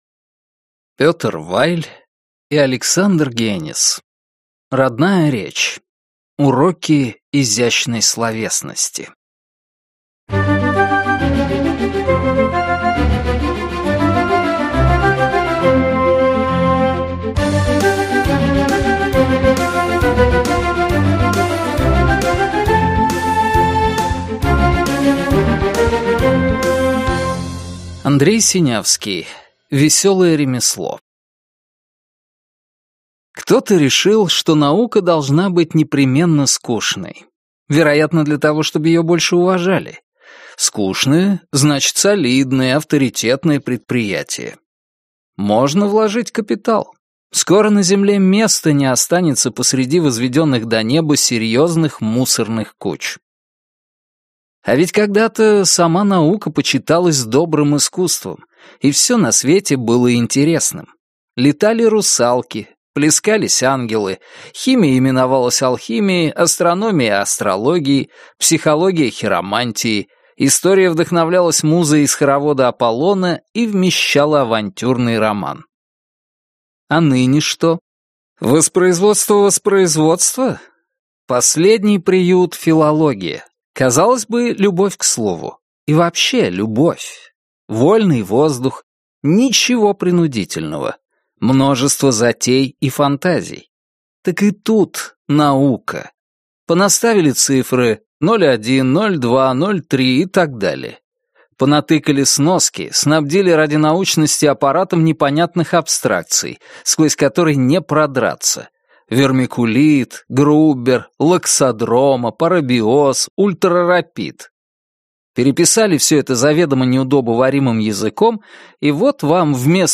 Аудиокнига Родная речь. Уроки изящной словесности | Библиотека аудиокниг